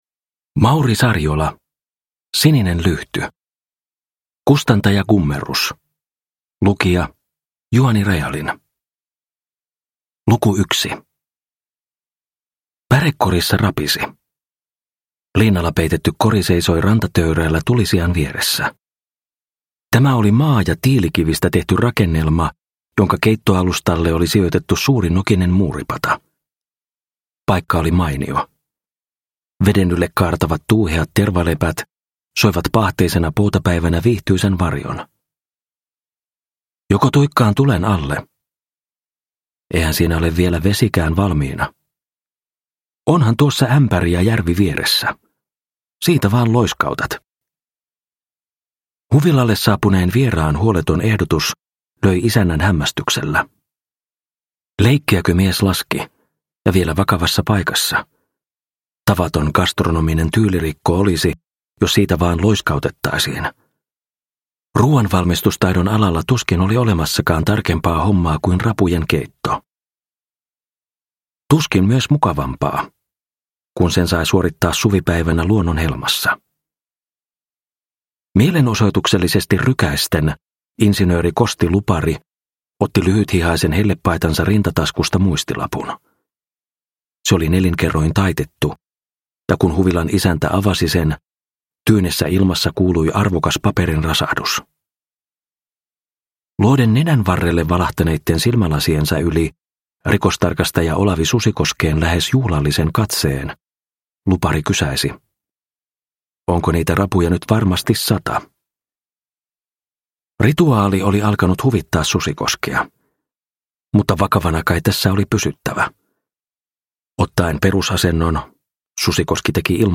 Sininen lyhty – Ljudbok – Laddas ner